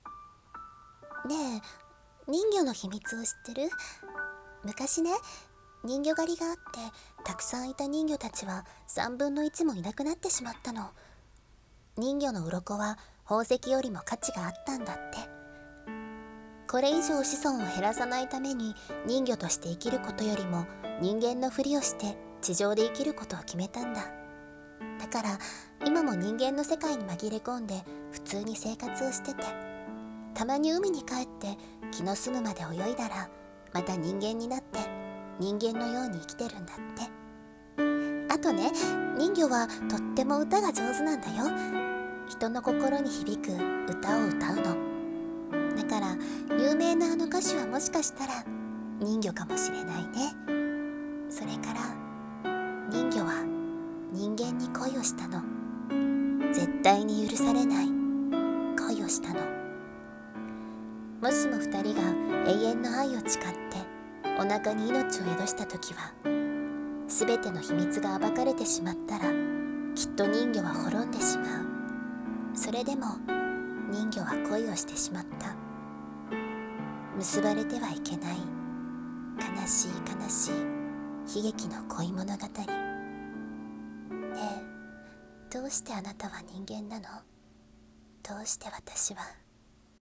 声劇「人魚の秘密」